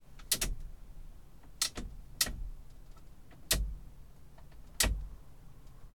Lada, Niva, t11, Var SFX, Windhshield Wiper Lever, Interior, AMBEO3.ogg